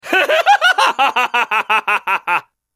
Risada Dio Brando
risada-dio-brando.mp3